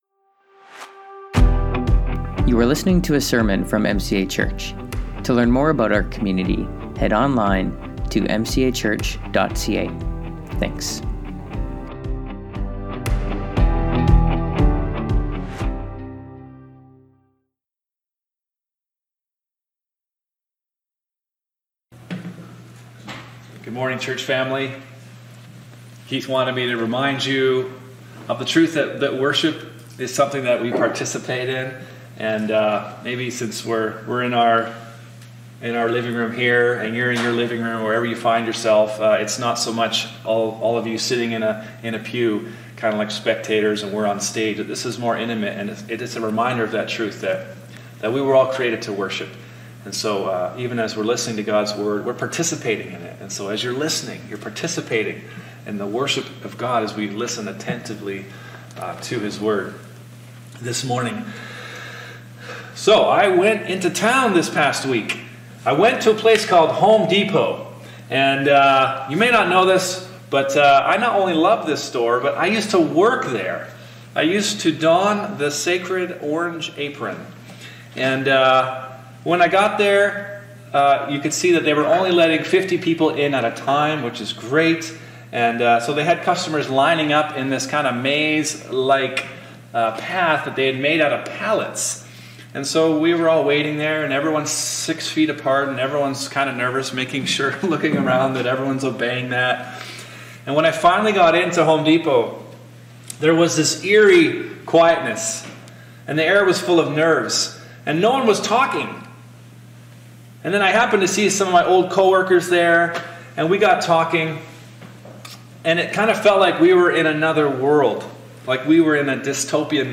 Sermons | Mission Creek Alliance Church